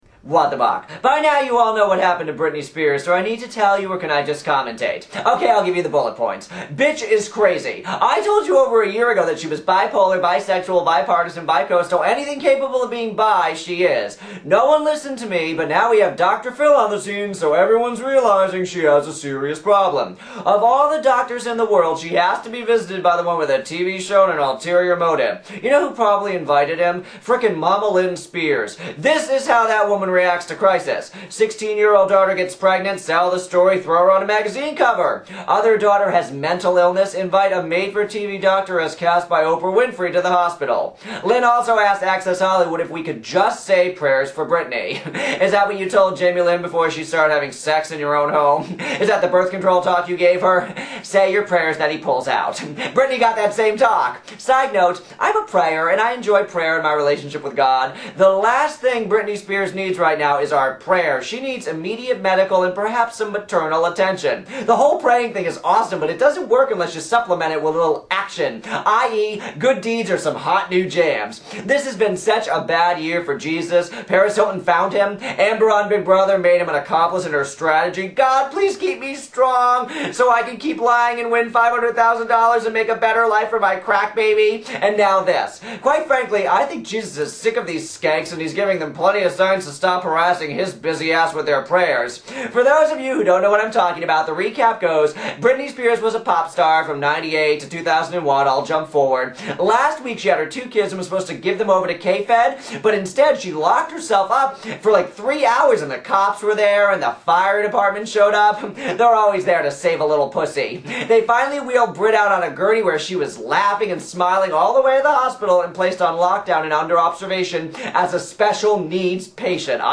Tags: Television What the Buck Show Michael Buckley Celebrity News News Program